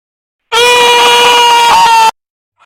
Pizza Tower Scream Sound Effect Free Download
Pizza Tower Scream